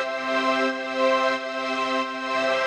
SaS_MovingPad05_90-C.wav